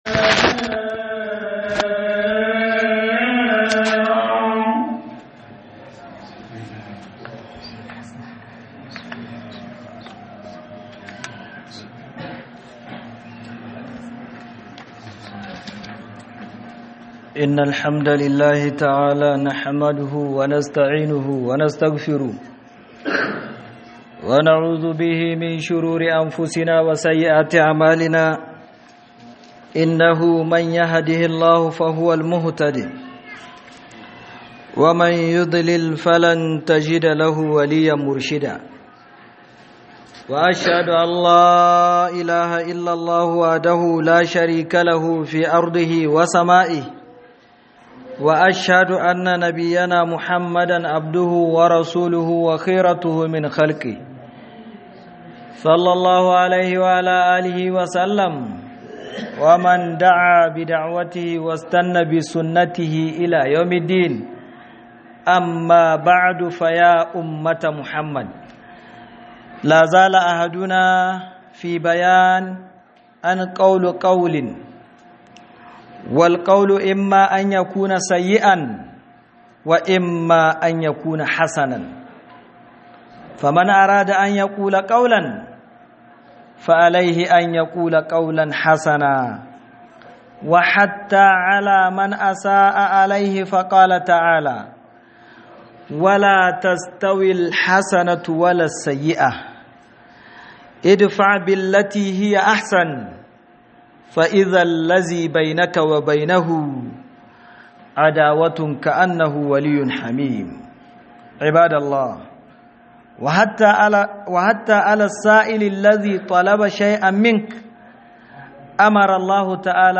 2026-04-17_KA GAYI MAGANA ME KYEW 3 - HUDUBA